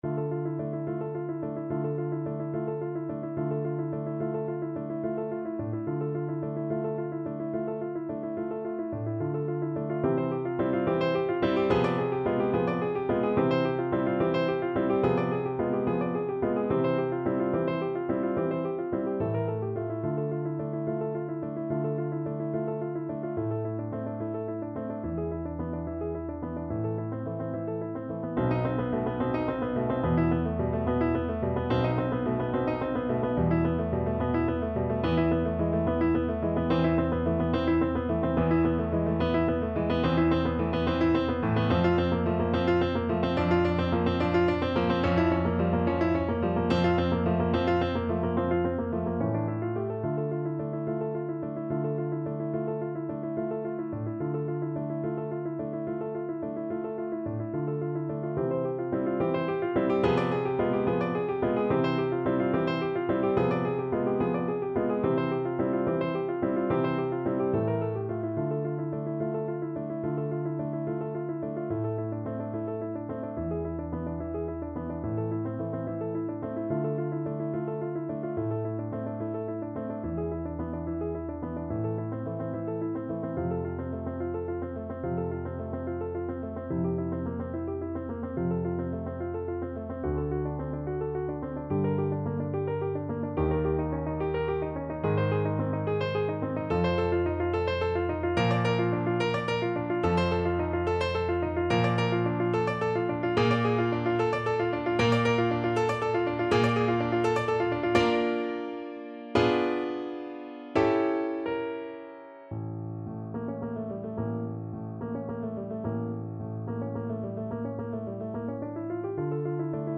E5-A6
Nicht zu geschwind (.=72)
6/8 (View more 6/8 Music)
Classical (View more Classical Voice Music)